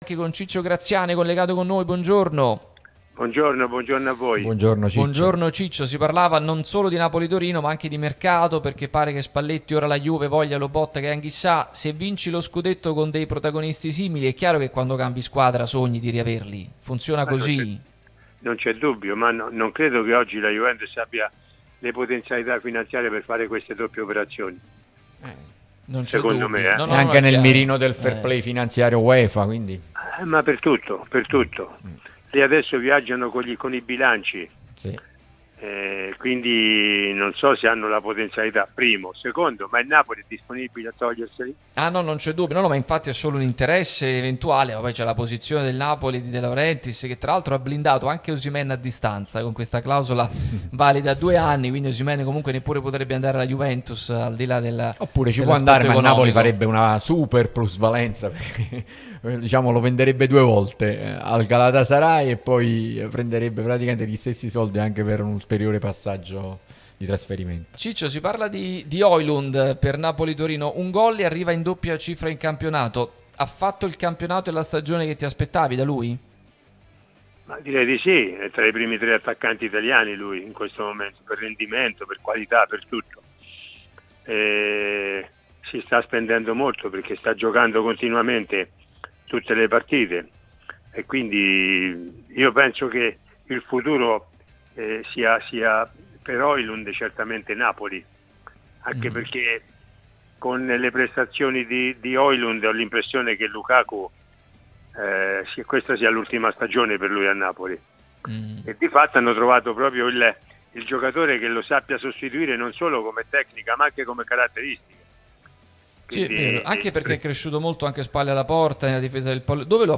Ciccio Graziani, ex attaccante, campione del mondo nel 1982, è intervenuto su Radio Tutto Napoli, prima radio tematica sul Napoli, che puoi seguire sulle app gratuite (scarica qui per Iphone o per Android), qui sul sito anche in video.